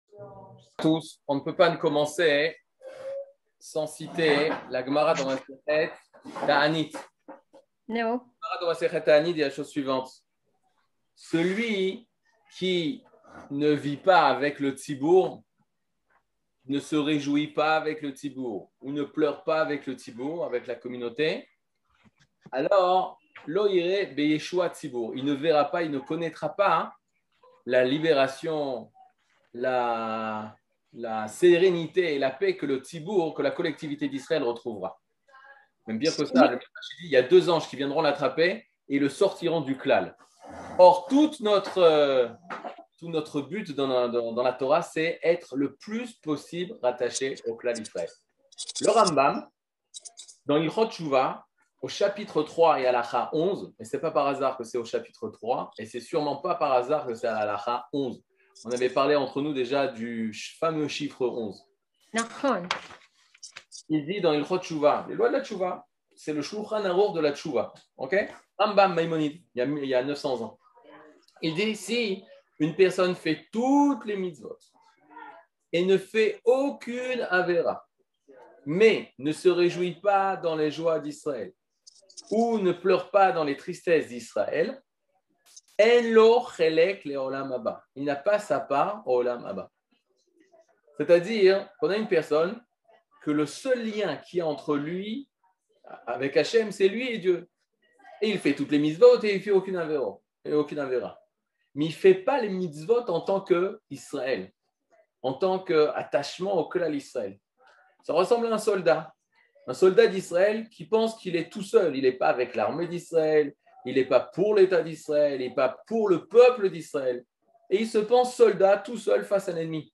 Catégorie Le livre du Kuzari partie 21 00:57:59 Le livre du Kuzari partie 21 cours du 16 mai 2022 57MIN Télécharger AUDIO MP3 (53.08 Mo) Télécharger VIDEO MP4 (109.61 Mo) TAGS : Mini-cours Voir aussi ?